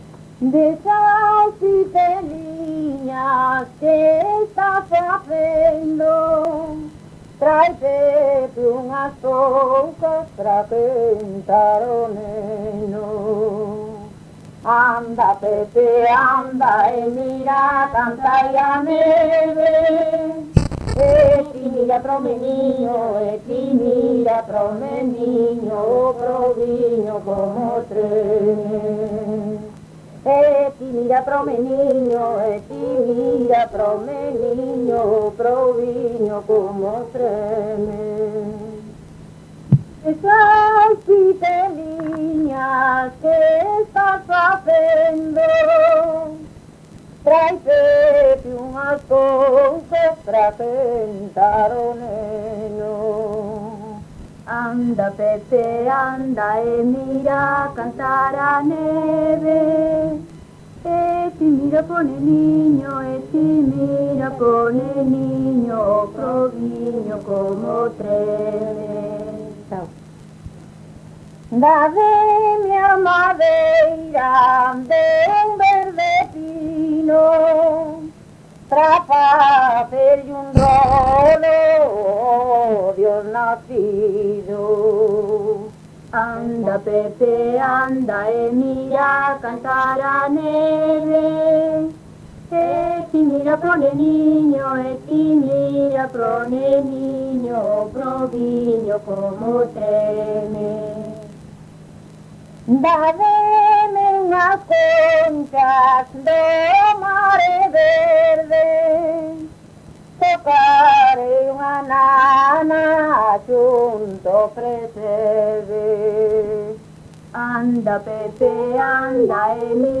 Tipo de rexistro: Musical
Áreas de coñecemento: LITERATURA E DITOS POPULARES > Cantos narrativos
Lugar de compilación: Chantada - A Grade (San Vicente) - Quintá
Soporte orixinal: Casete
Instrumentación: Voz
Instrumentos: Voz feminina